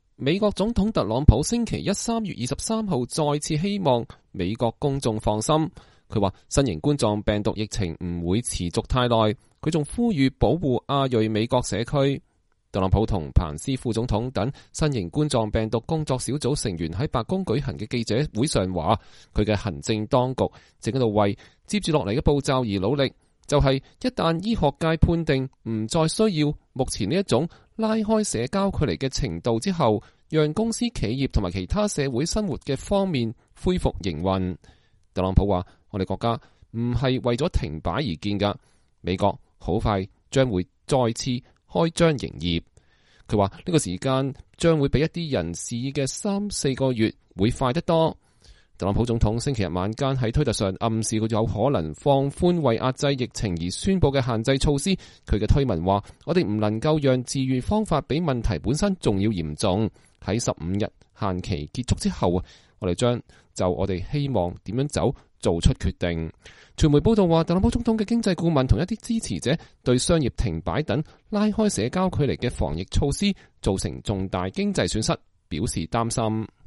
特朗普總統在白宮就新冠病毒疫情舉行記者會。(2020年3月23日)